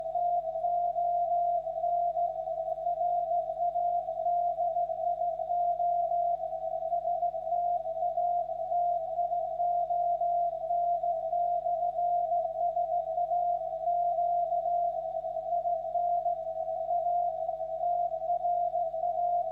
- Rahmenantenne, Ferritantenne, SDR-RX (selbstgebaut)